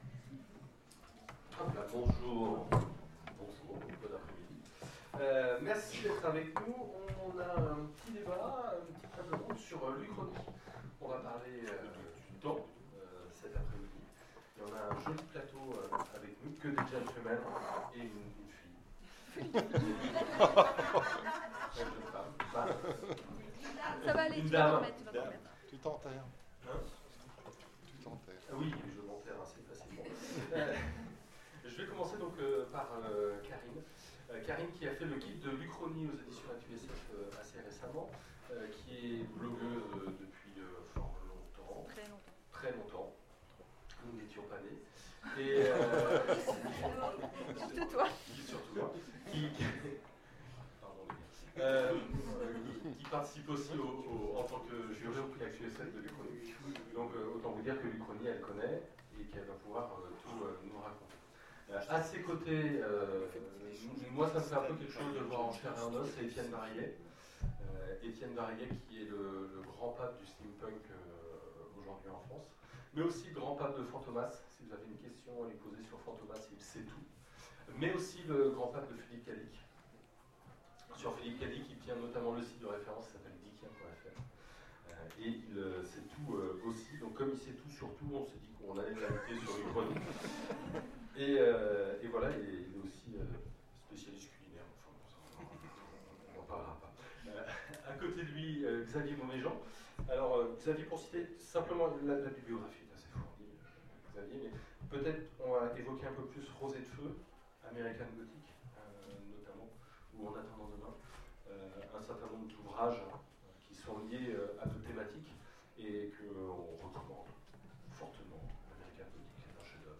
Les Oniriques 2015 : Table ronde Les aiguilleurs du temps, l'uchronie